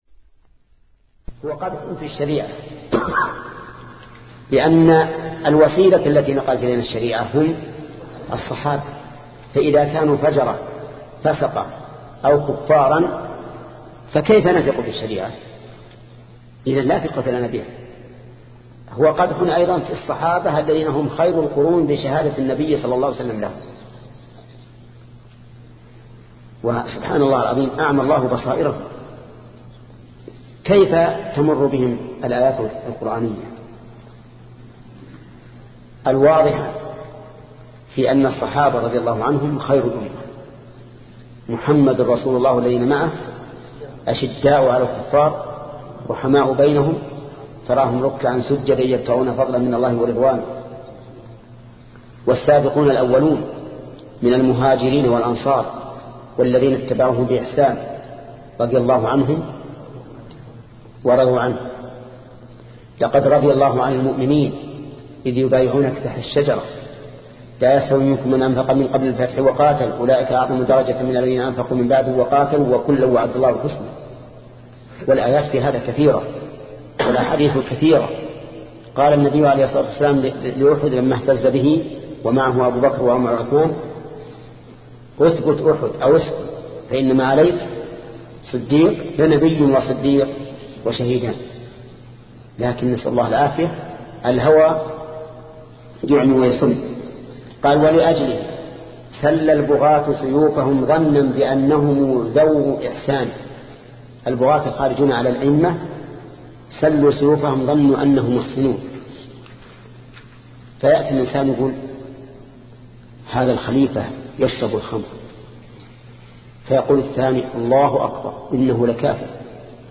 شبكة المعرفة الإسلامية | الدروس | التعليق على القصيدة النونية 20 |محمد بن صالح العثيمين